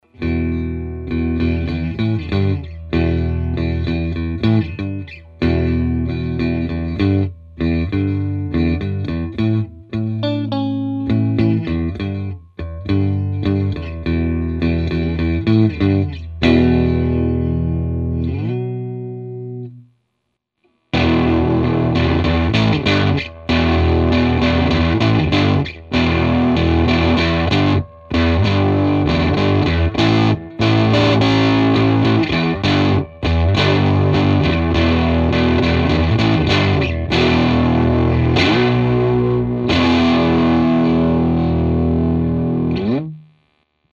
Bypass first, then effect(s)
• OVERDRIVE: Medium-hard limiter – medium to gritty limit.